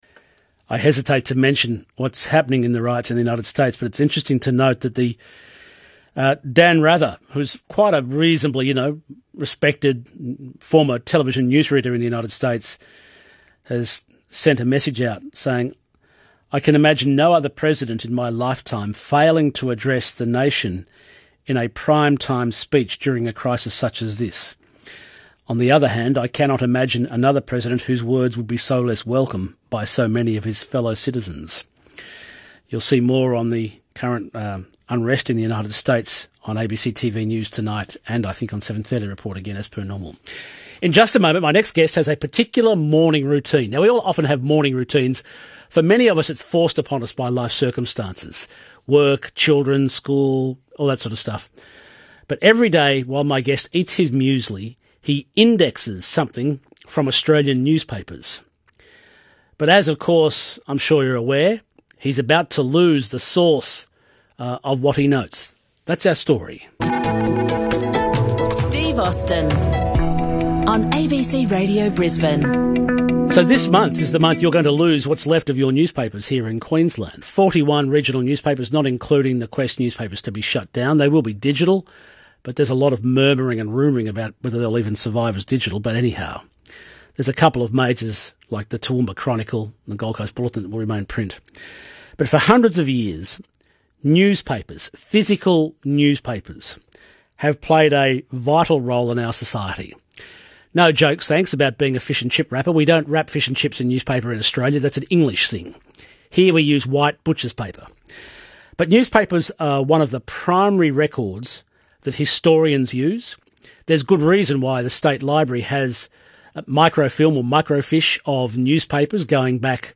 ABC (Brisbane) radio interview 2 June 2020